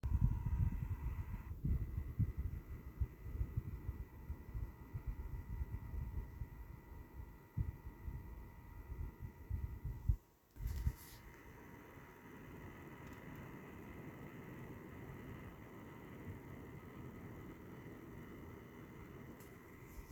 So jetzt kommt der knaller habe jetzt die Gaming OC von Gigabyte und was ist.... die klingt wie ein Rasenmäher. Permanentes kratzendes rattern ab 1100 RPM. meiner Meinung hat Gigabyte ein generelles Problem mit den Lüftern.